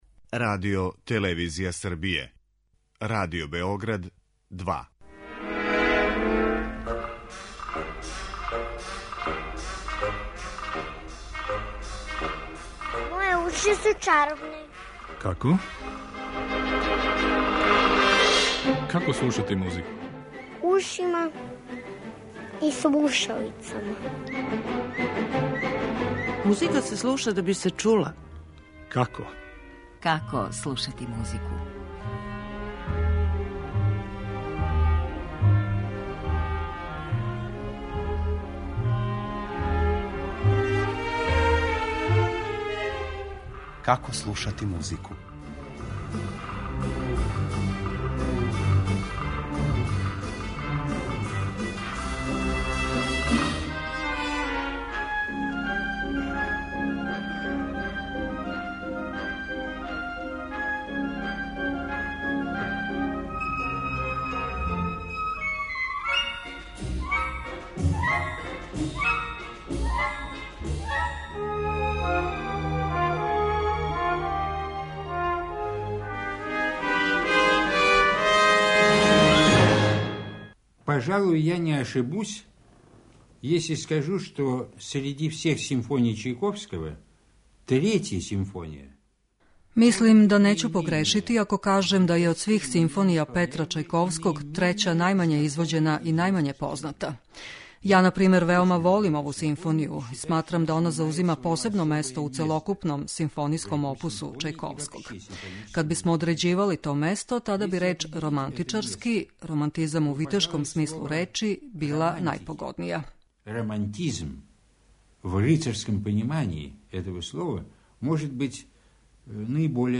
Чајковски овога пута не посеже за фолклорним темама, оне су сада поетски евокативне, уводи одређене концертантне елементе у средњим ставовима, и више не инсистира на једноставним музичким идејама у изградњи великих одсека, сада му је важнија равнотежа између теме и елаборације.